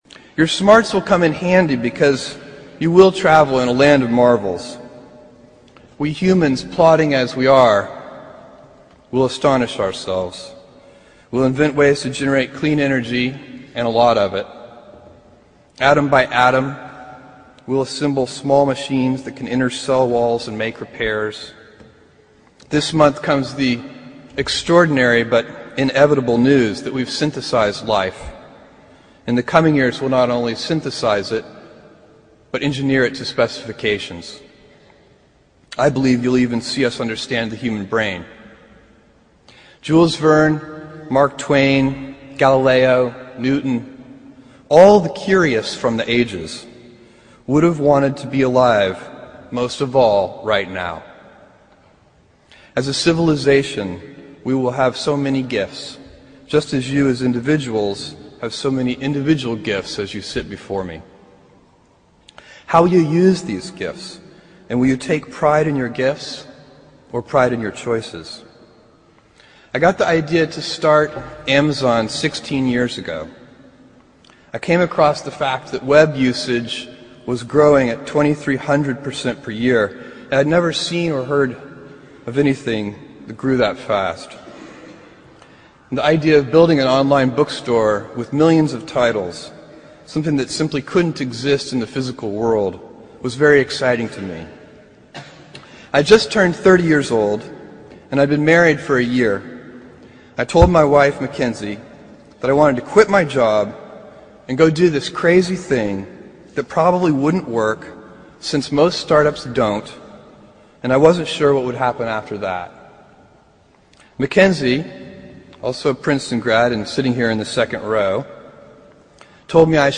公众人物毕业演讲 第304期:亚马逊创始人贝佐斯普林斯顿大学毕业演讲(3) 听力文件下载—在线英语听力室